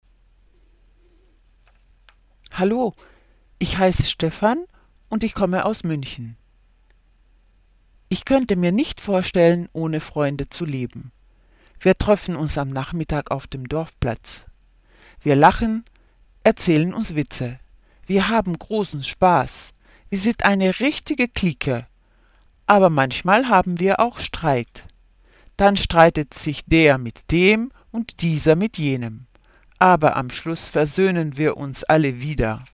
fichiers son de la page du manuel (enregistrés par une collègue d'allemand):